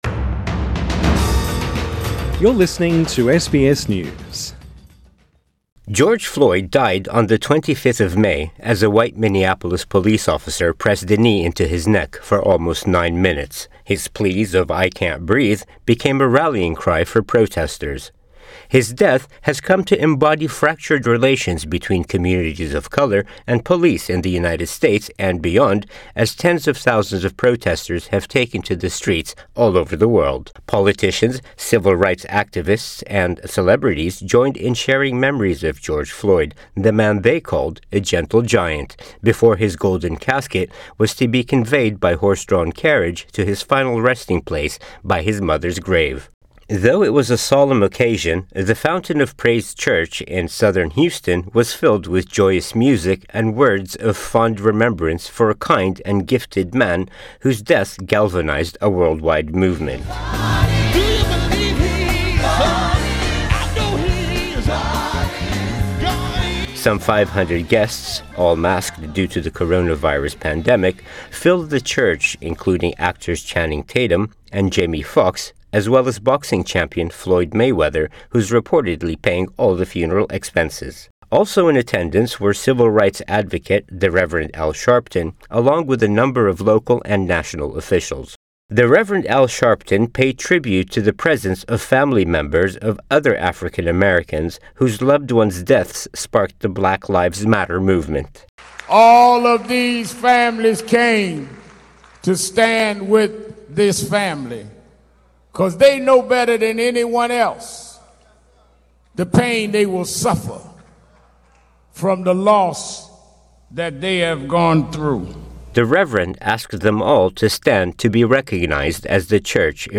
Funeral service held for George Floyd
The Rev Al Sharpton speaks during the funeral for George Floyd Source: AAP